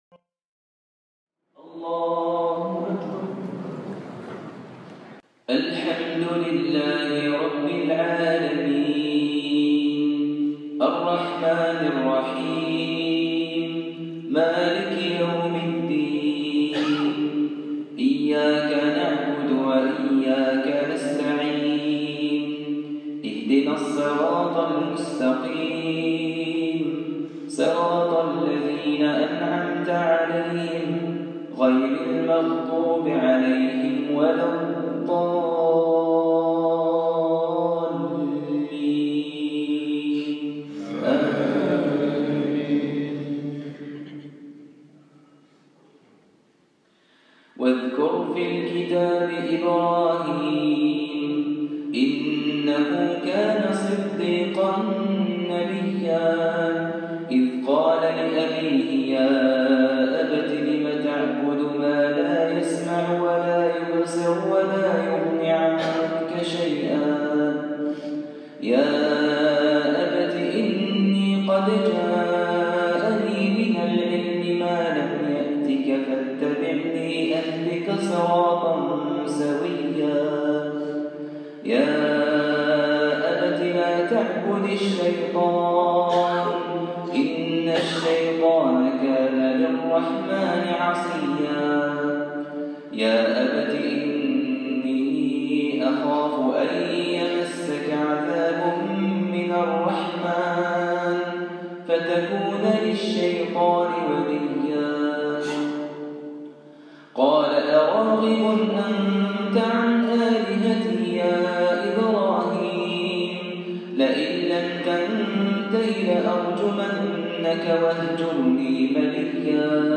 • صلاة الفجر رمضان 2016